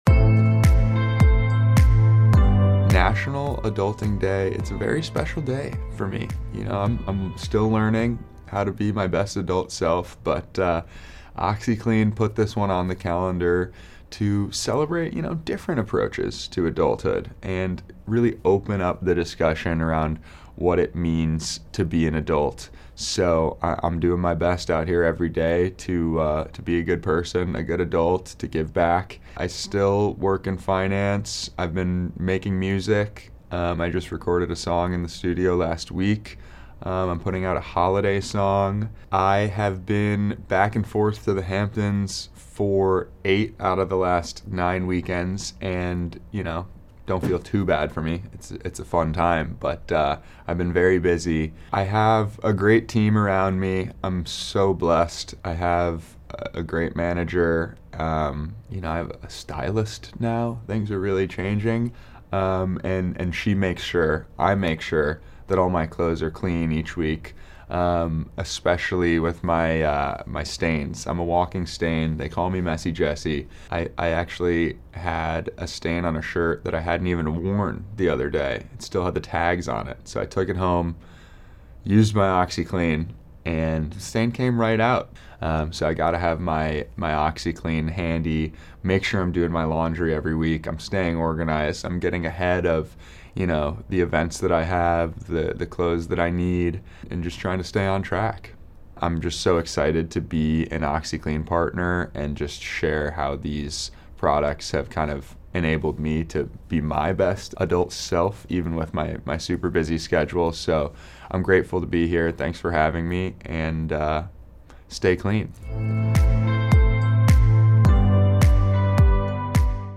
September 19 is National Adulting Day, a day to celebrate the privileges and, let’s face it, the challenges of embracing adulthood. Bravo’s Summer House star Jesse Solomon has teamed up with OxiClean to help make everyday life a little easier. Jesse stopped by the LifeMinute Studios to share his journey of navigating life’s messes and finding success as a young adult.